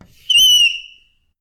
sounds_chalk_screech_01.ogg